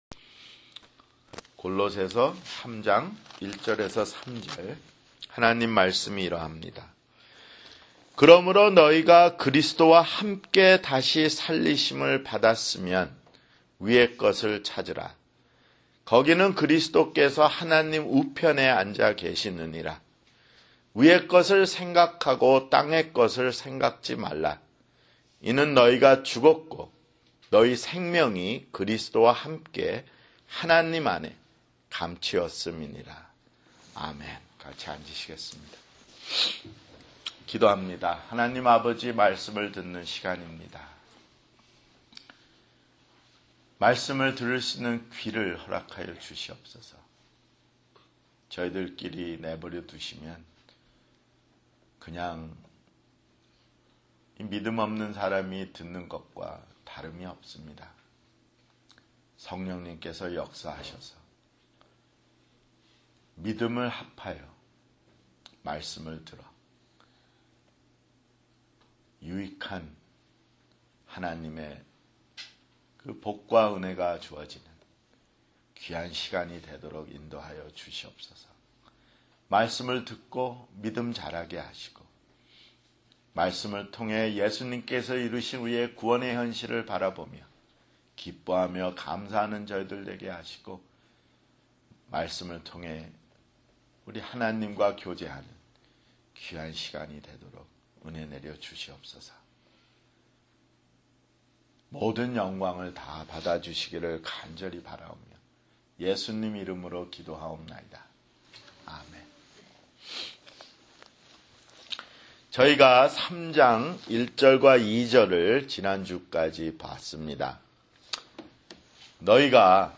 [주일설교] 골로새서 (56)